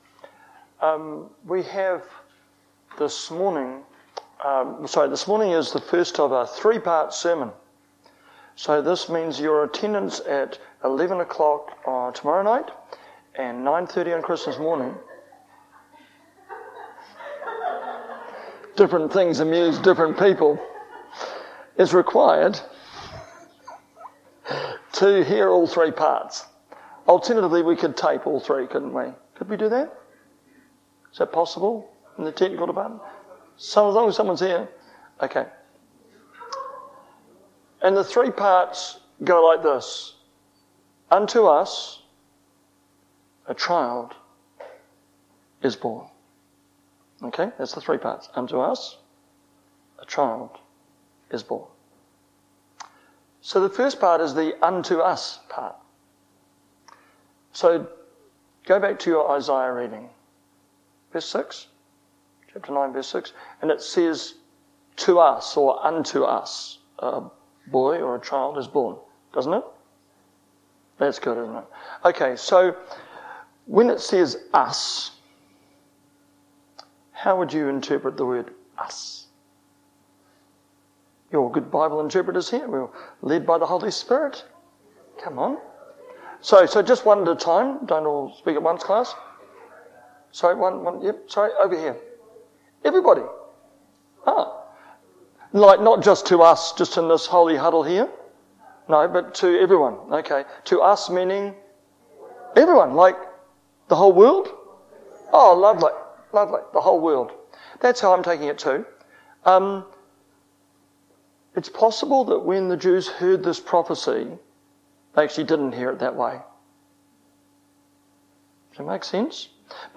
sermon23dec.mp3